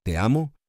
1. "Te" rhymes with "day": It's a short, crisp sound.
3. "Amo" = "AH-moh": Simple and clean - don't add extra sounds.